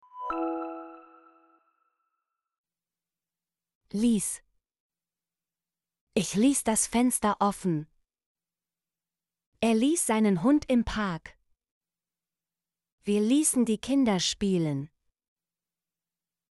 ließ - Example Sentences & Pronunciation, German Frequency List